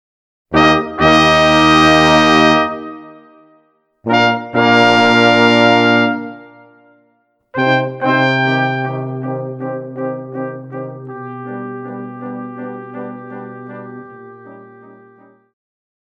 此套曲庫卡收集了 9 首專為低音號改編的經典爵士名曲。
主奏樂器 低音號